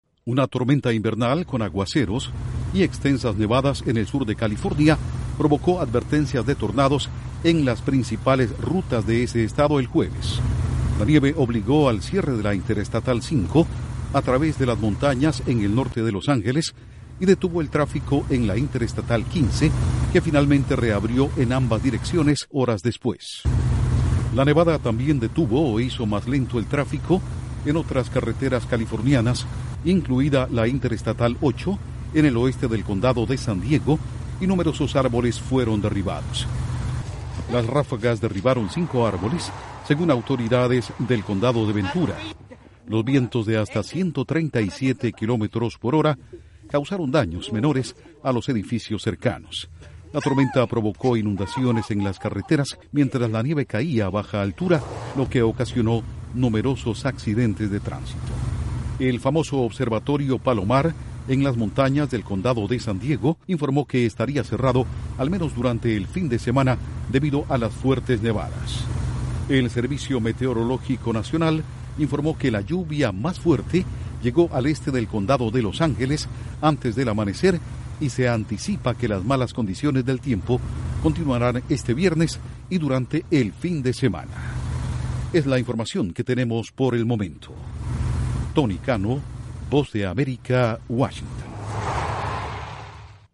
Poderosa tormenta golpea el sur de California con lluvia y nieve. Informa desde la Voz de América en Washington